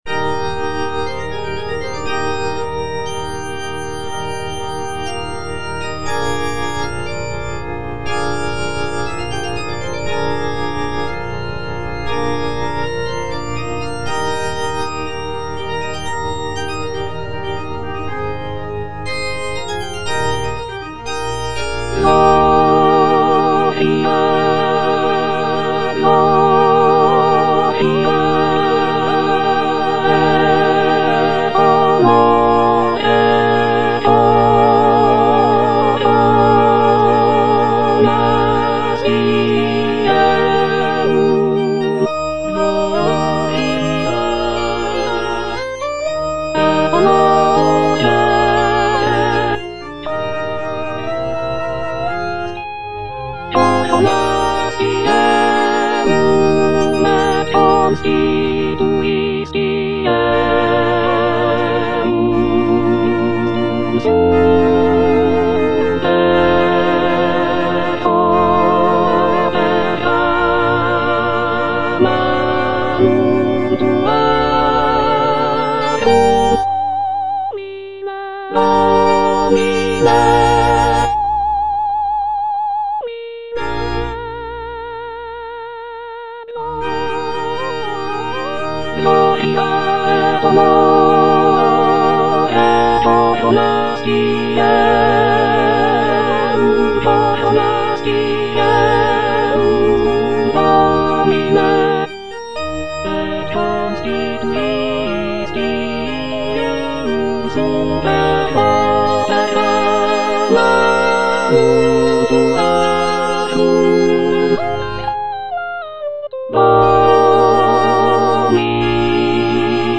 The work features a grand and powerful sound, with rich harmonies and expressive melodies.
C.M. VON WEBER - MISSA SANCTA NO.1 Offertorium - Soprano (Emphasised voice and other voices) Ads stop: auto-stop